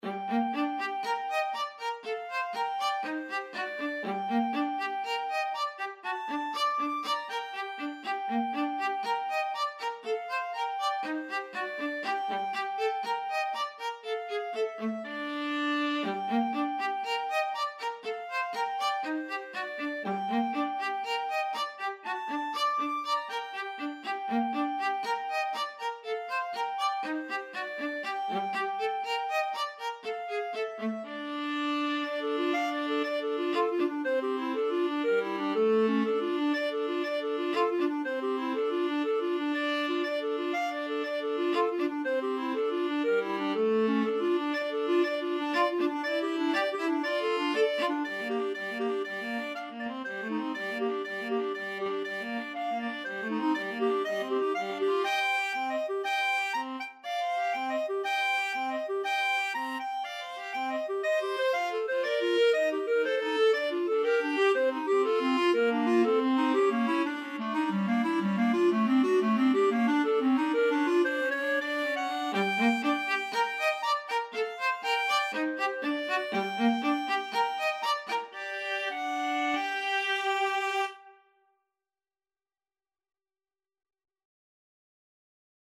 Free Sheet music for Clarinet-Viola Duet
2/2 (View more 2/2 Music)
G minor (Sounding Pitch) (View more G minor Music for Clarinet-Viola Duet )
Allegro (View more music marked Allegro)
Classical (View more Classical Clarinet-Viola Duet Music)